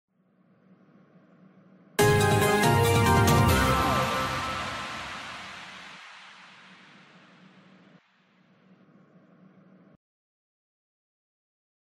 PLAY Next question game show
game-show-next-question.mp3